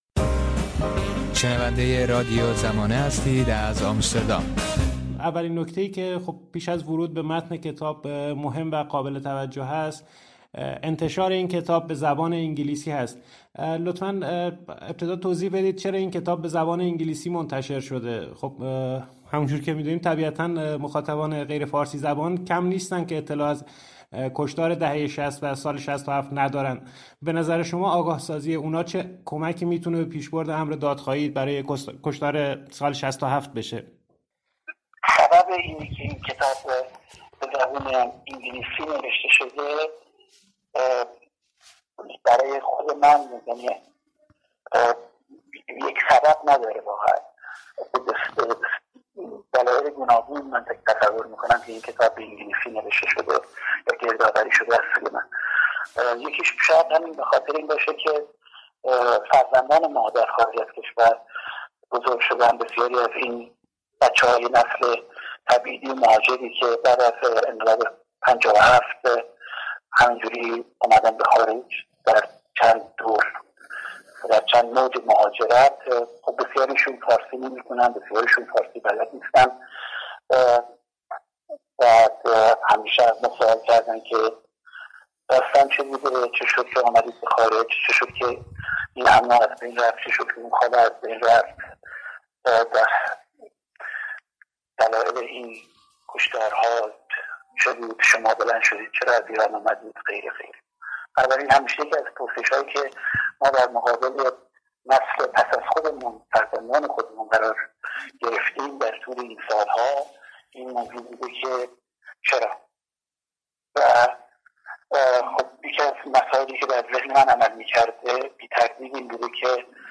گفت‌وگوی رادیو زمانه